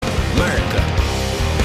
PLAY Merica Chorus